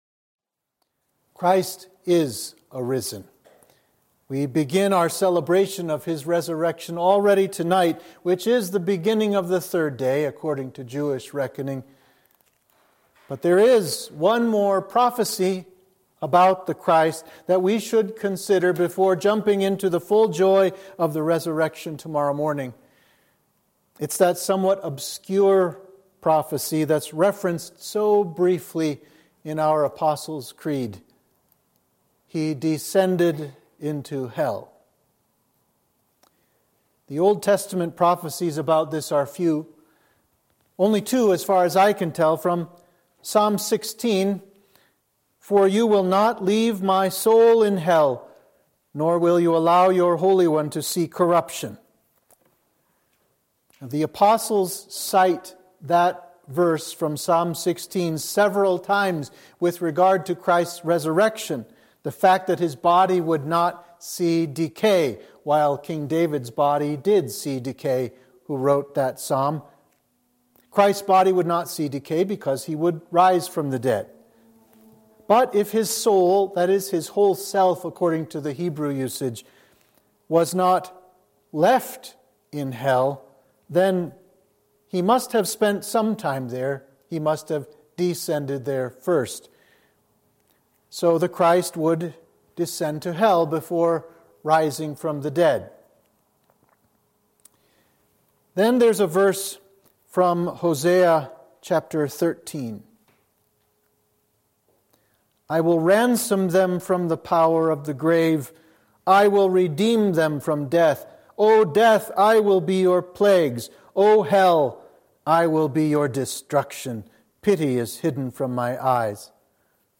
Sermon (audio)